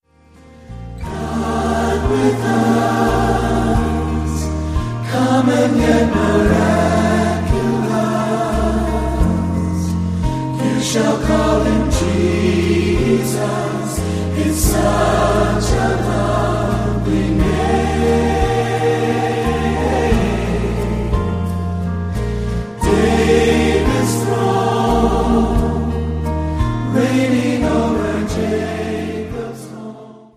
• Sachgebiet: Southern Gospel